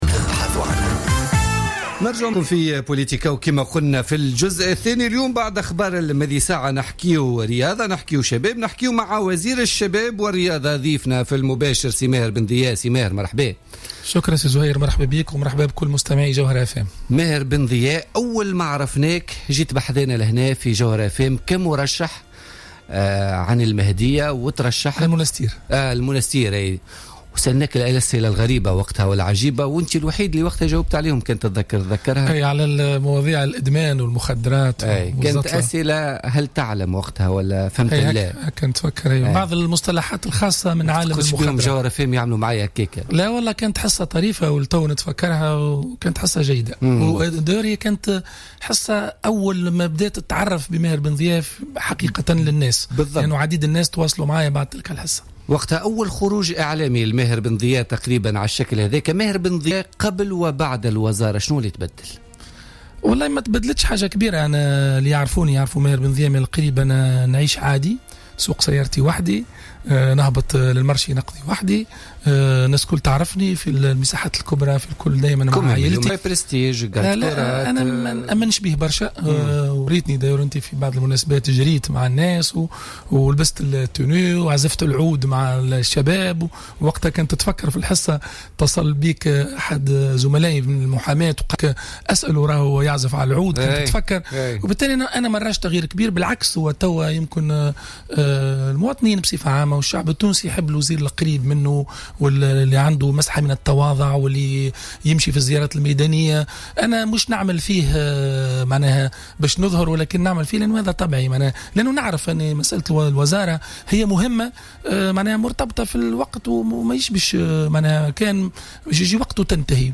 أكد وزير الشباب والرياضة ماهر بن ضياء ضيف بوليتيكا اليوم الإثنين 4 أفريل 2016 أن الوزارة لاتحظى إلى حد الان بالأهمية البالغة على غرار بعض الدول الأخرى الذين يولون أهمية وقيمة كبرى لوزارة الشباب والرياضة .